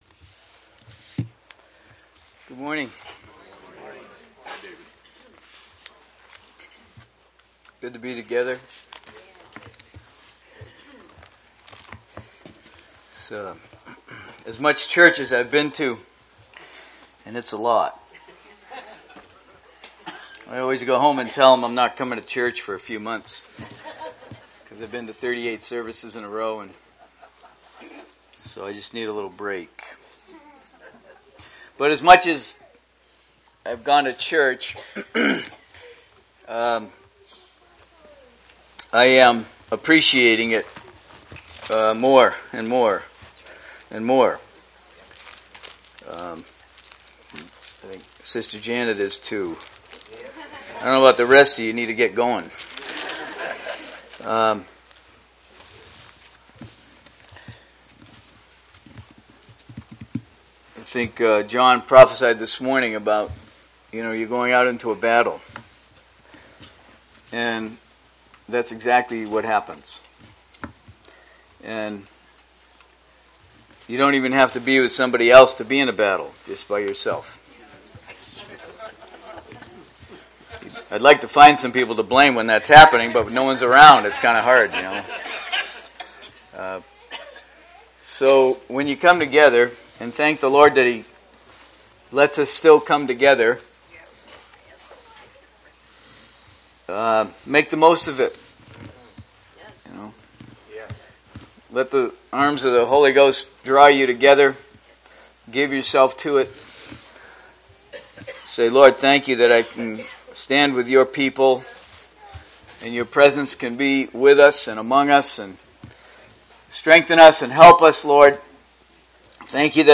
Posted in 2017 Shepherds Christian Centre Convention